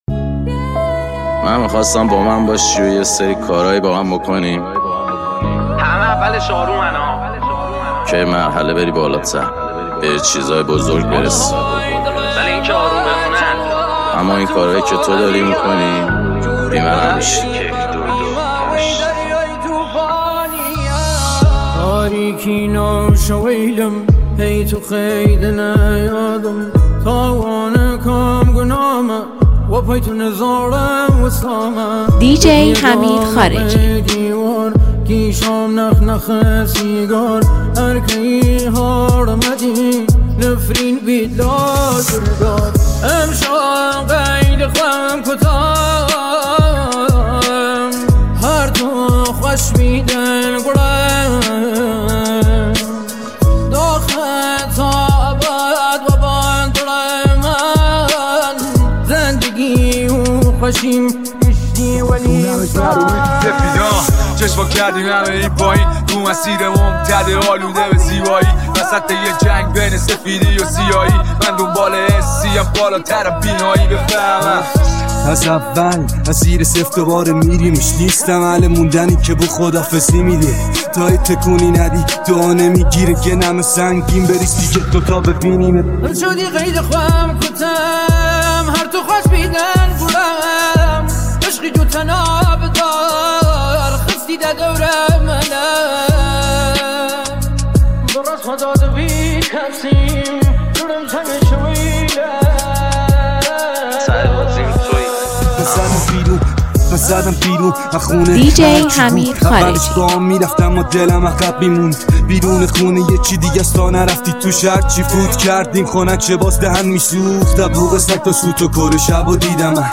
❣این میکس رپ ترکیبی رو از دست ندید